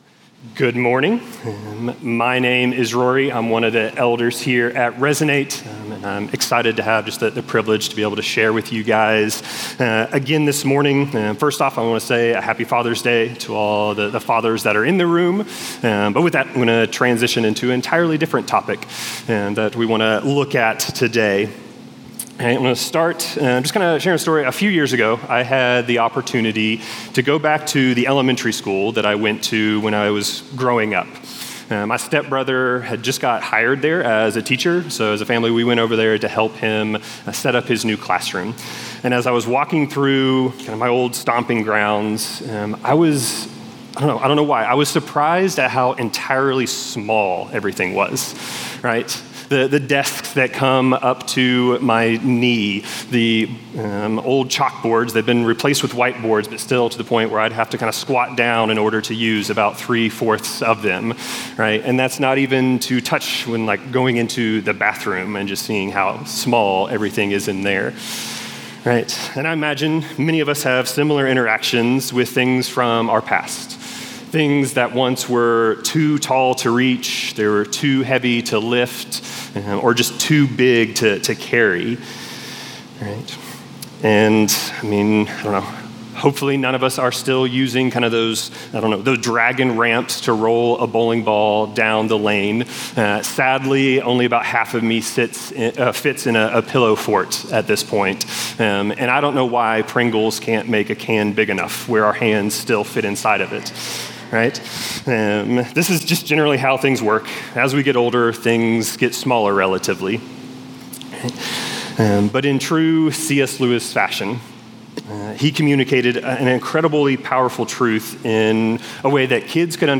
In a world that idolizes hustle and hides exhaustion, this sermon invites us to encounter the God who doesn’t shame our weariness but meets us in it—with strength, rest, and the grace to keep going.